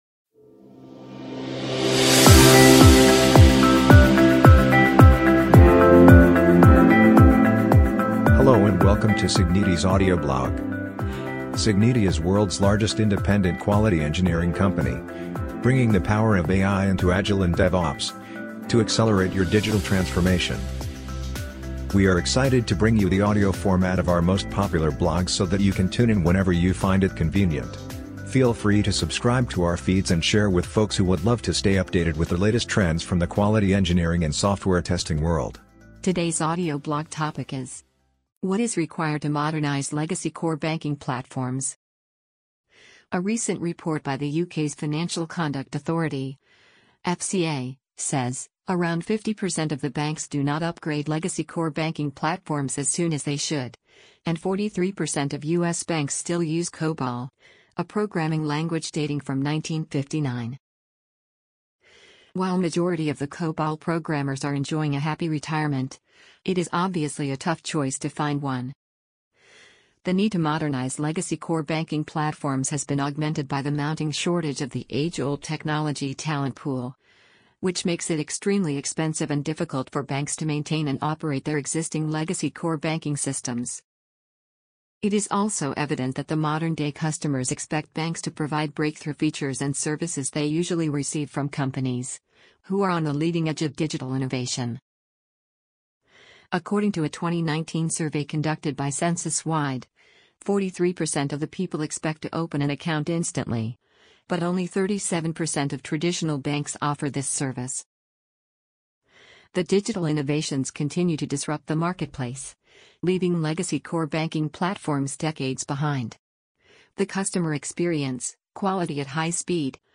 amazon_polly_14637.mp3